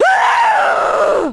Index of /Downloadserver/sound/zp/zombie/female/
zombi_infection_female_2.mp3